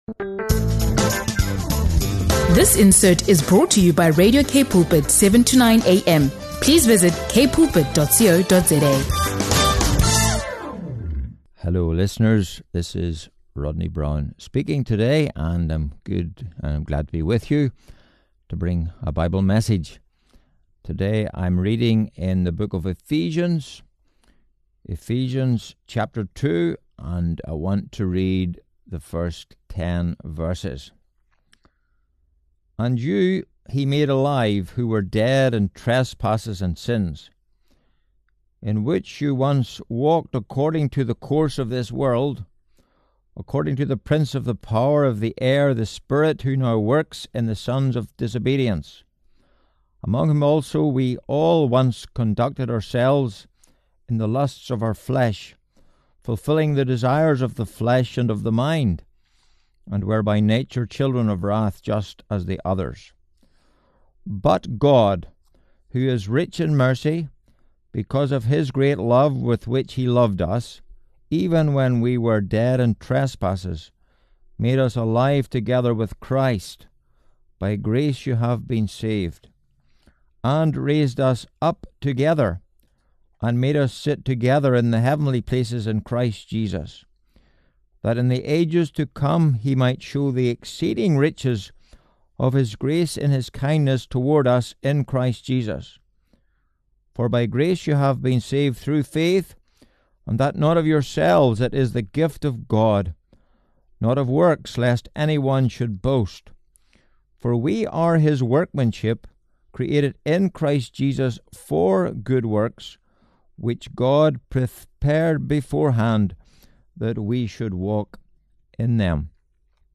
In this powerful Bible teaching